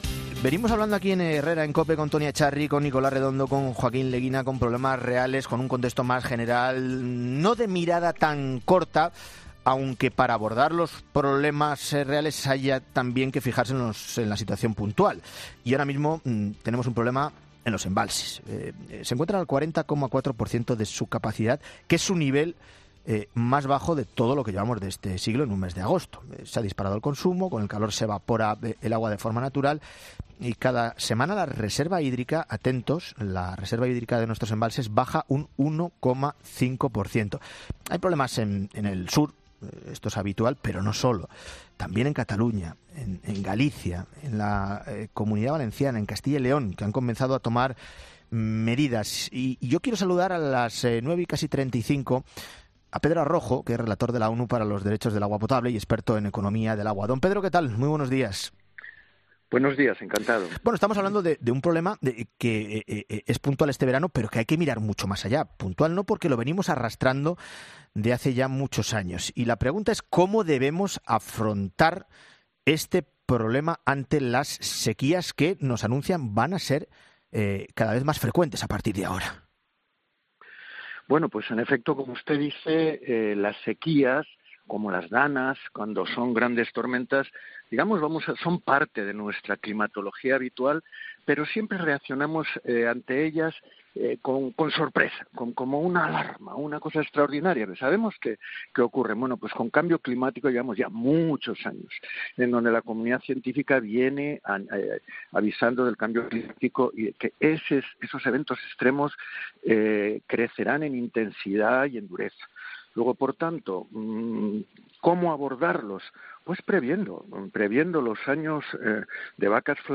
Para saber qué es lo que realmente se debe hacer ante una situación como la que nos encontramos en estos momentos en nuestro país, hablamos en ‘Herrera en COPE’ con Pedro Arrojo, relator de la ONU para los derechos del agua potable que lo primero que nos aclara es que “el nivel de los embalses es una parte pequeña de nuestras reservas de agua” explicando que “nuestra reserva de agua son los acuíferos, los acuíferos subterráneos a los que estamos sobreexplotando desde hace muchos años”.